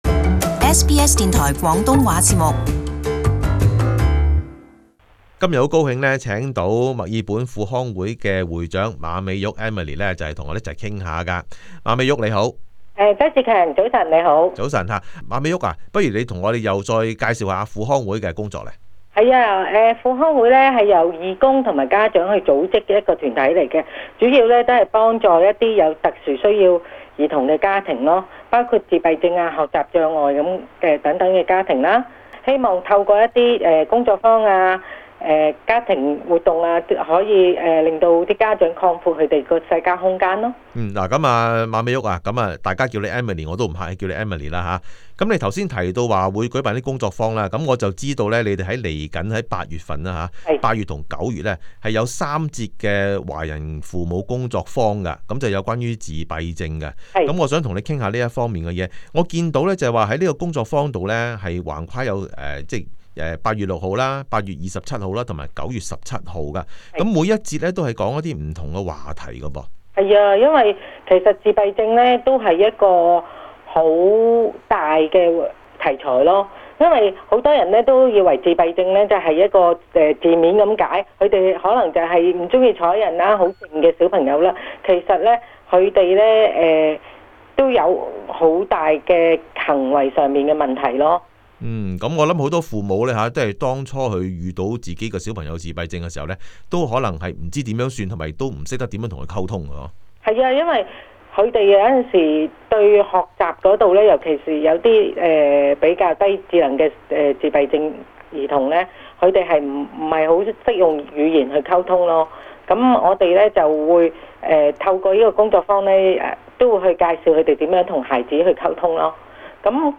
【專訪】輔康會-華人父母工作坊